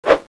Index of /Downloadserver/sound/weapons/
balrog9_slash2.mp3